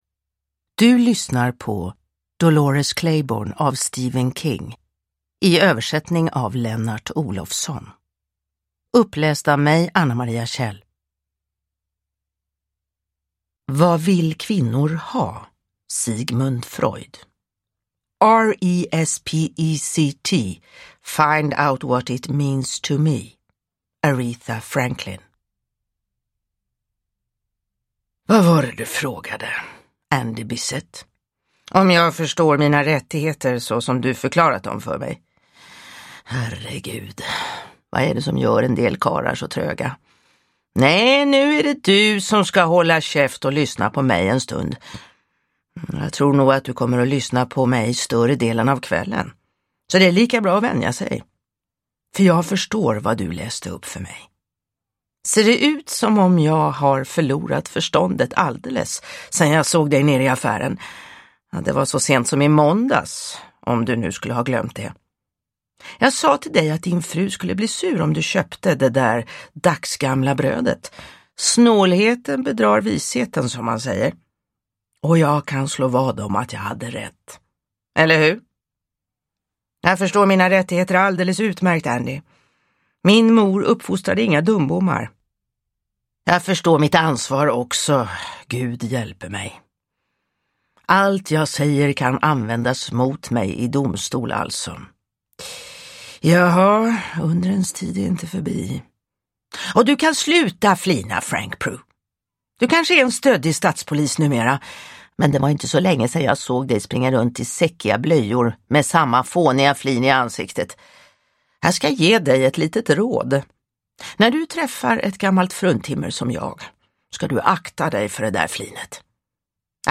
Dolores Claiborne – Ljudbok – Laddas ner